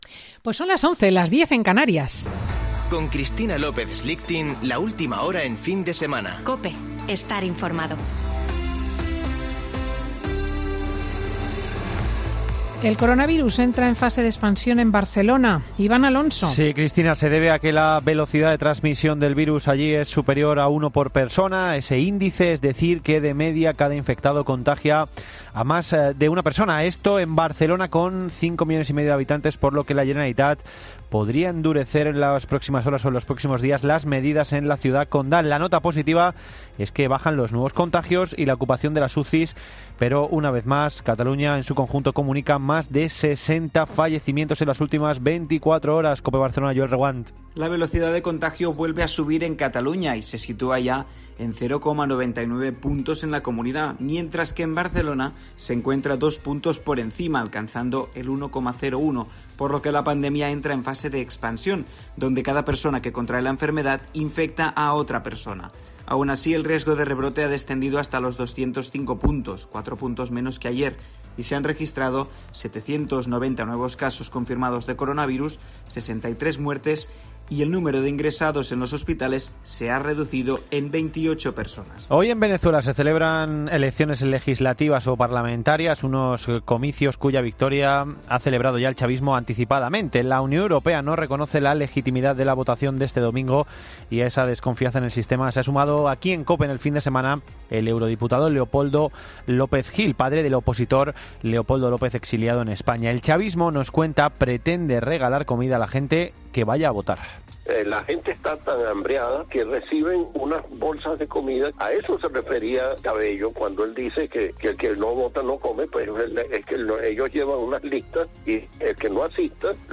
Boletín de noticias COPE del 6 de diciembre de 2020 a las 11.00 horas